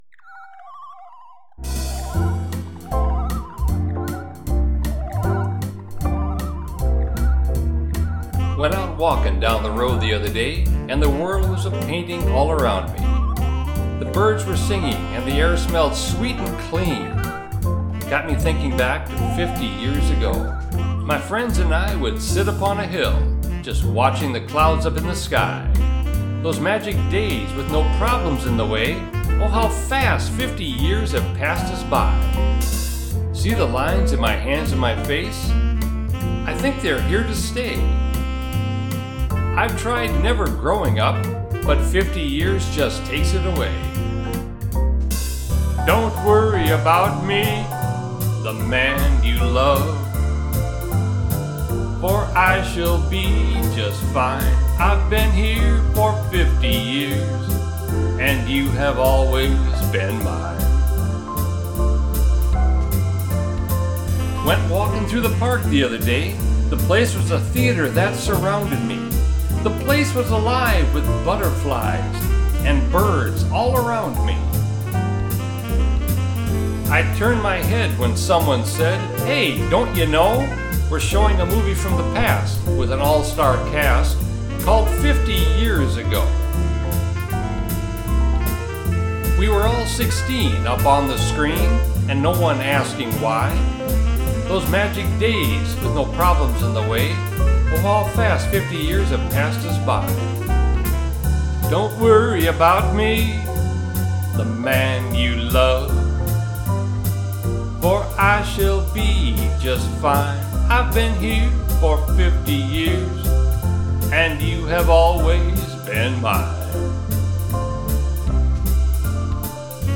Hear the song, Simply Walking.
narrating and singing